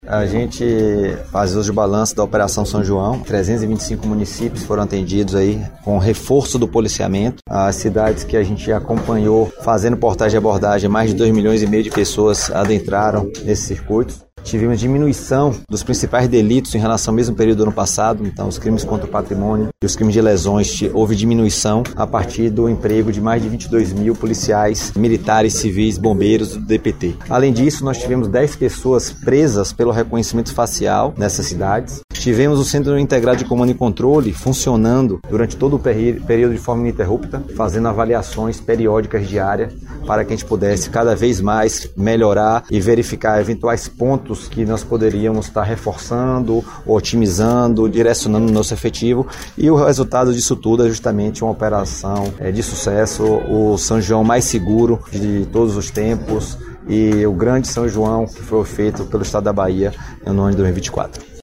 Sonora do Secretário de segurança
SONORA_MARCELO_WERNER_BALANCO_SSP.mp3